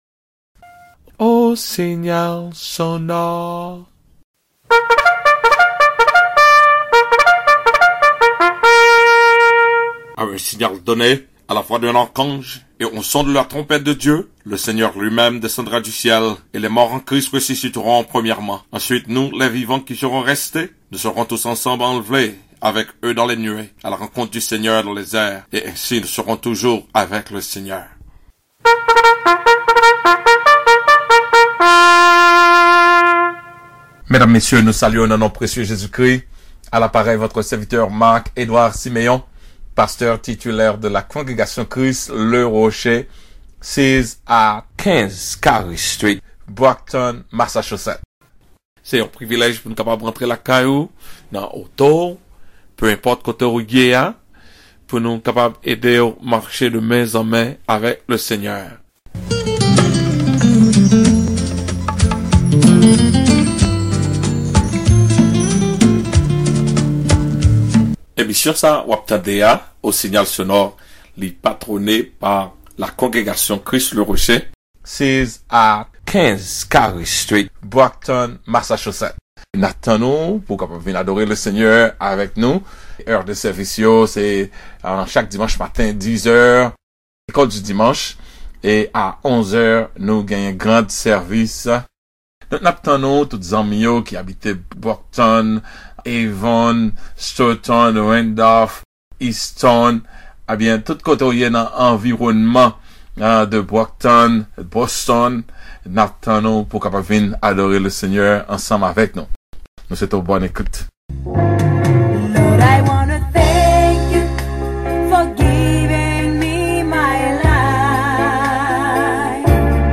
CLICK ABOVE TO HEAR THE AUDIO VERSION CUT FOR RADIO